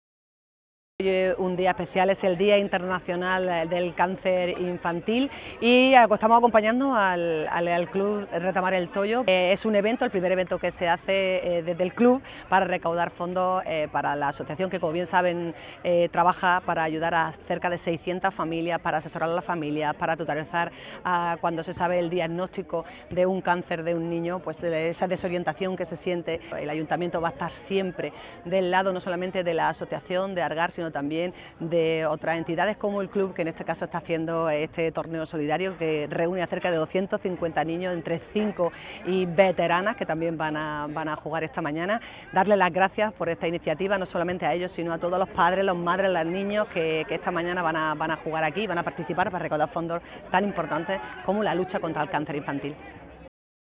ALCALDESA-TORNEO-SOLIDARIO-TOYO.wav